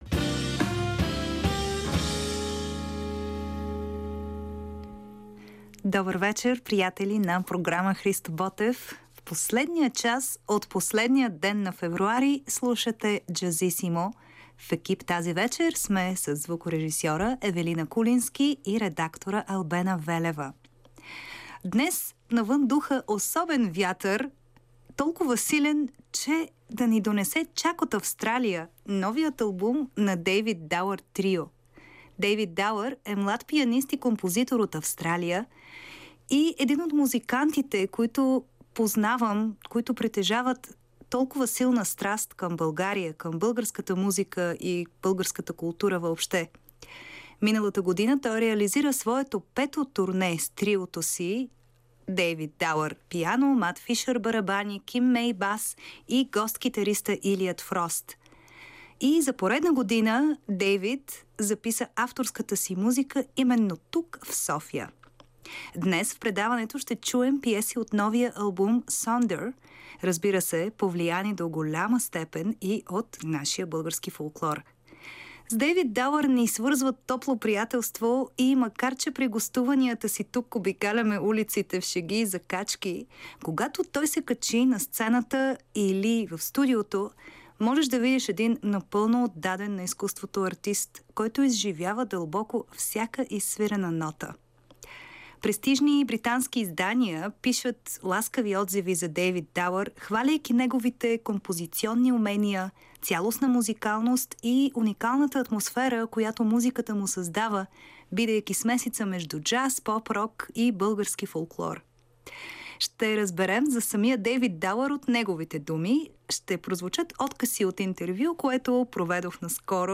Слушайте Jazzissimo в петък от 23:00 по програма „Христо Ботев“ на БНР.